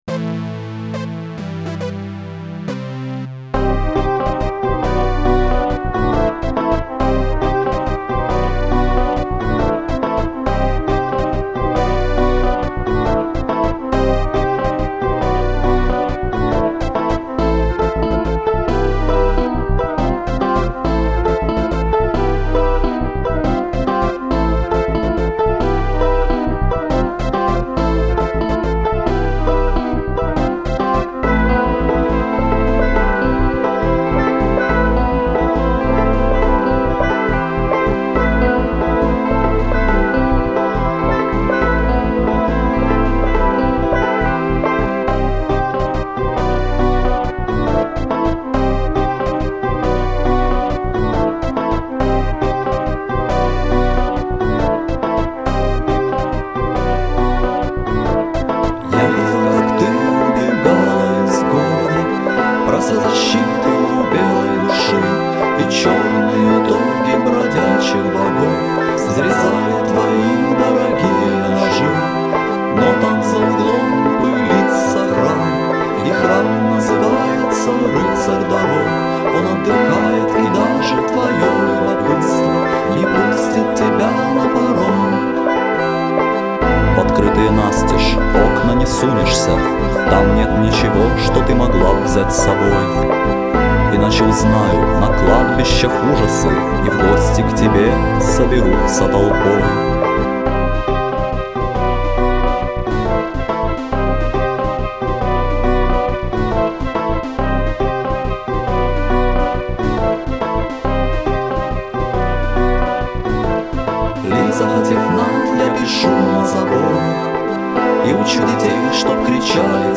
вокал
гитара
синтезаторы.